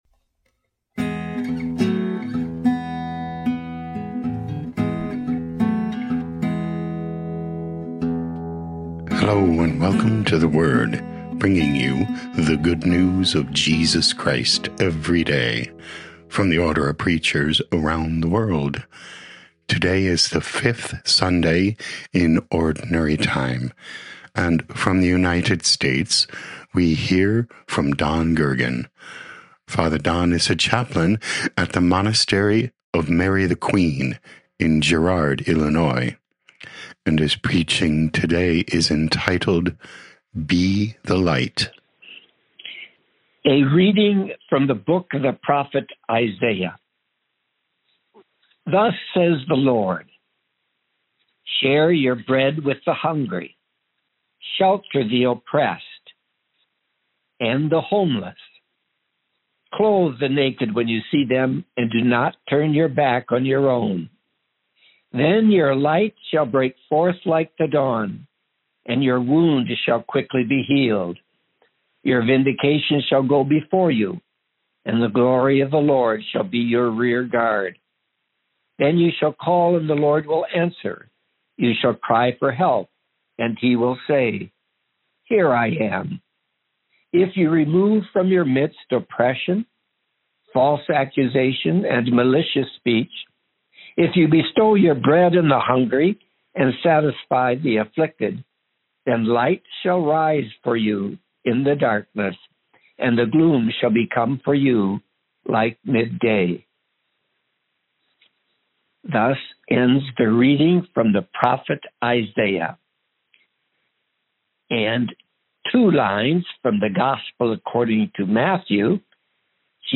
Preaching